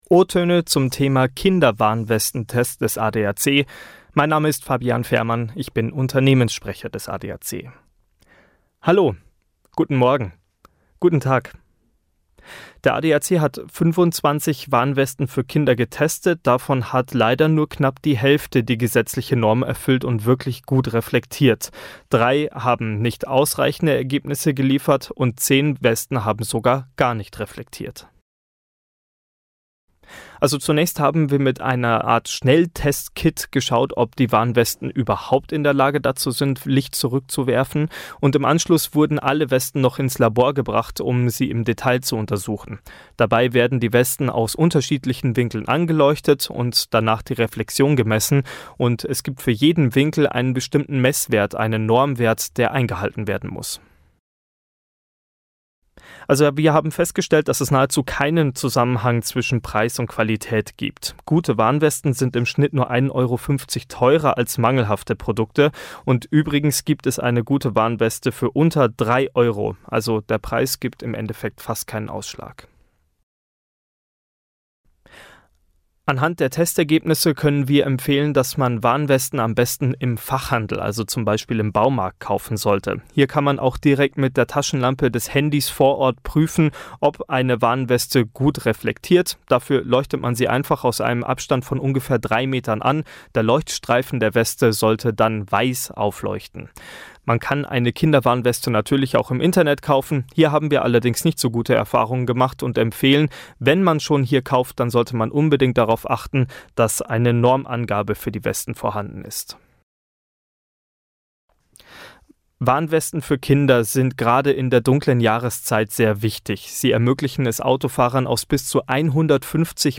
o-toene_adac_kinderwarnwesten-test-2025.mp3